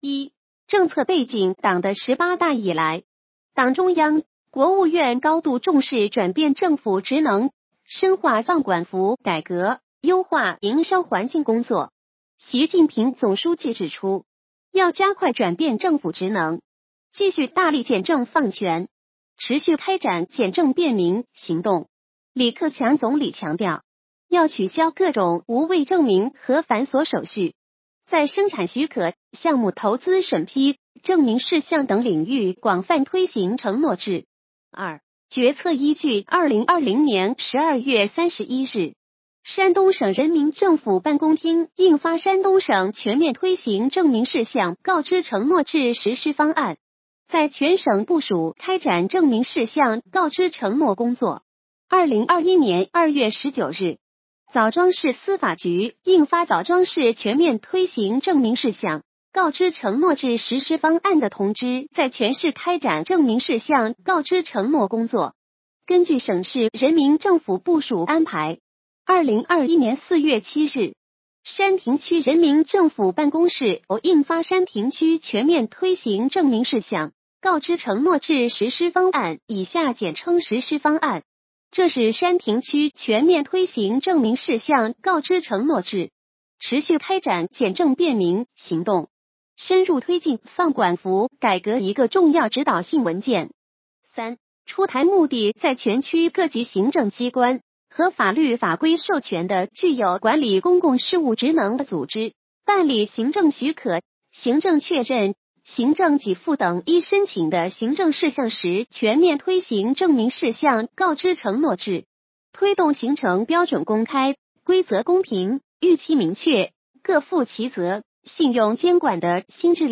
语音解读：山亭区人民政府办公室关于印发《山亭区全面推行证明事项告知承诺制实施方案》的通知